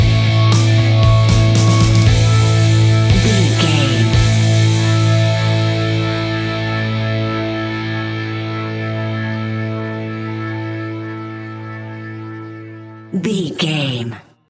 Aeolian/Minor
hard rock
blues rock
distortion
Rock Bass
Rock Drums
heavy drums
distorted guitars
hammond organ